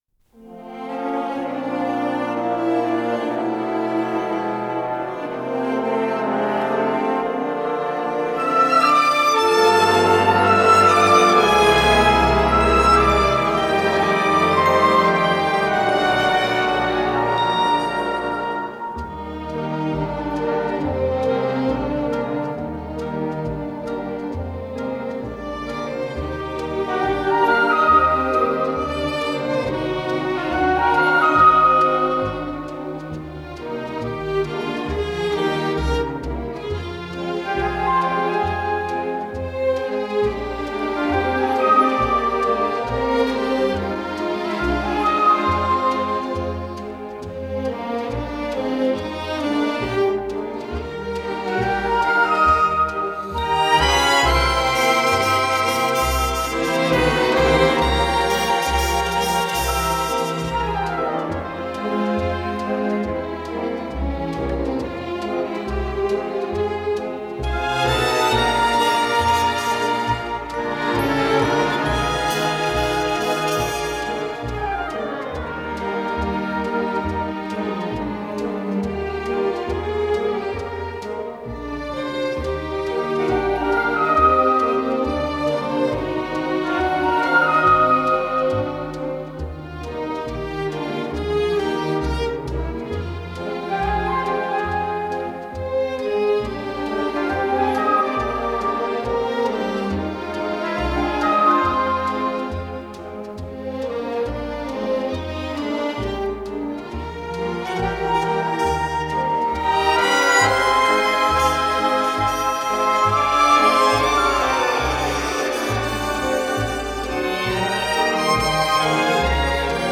Скорость ленты38 см/с